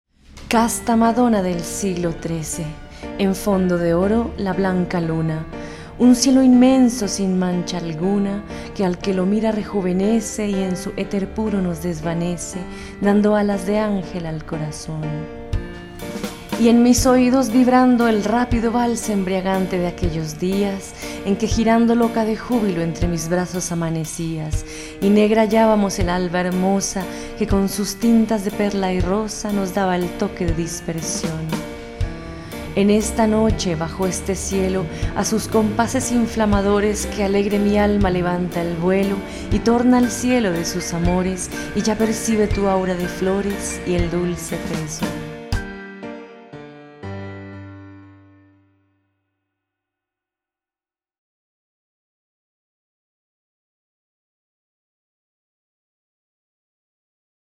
lectura musicalizada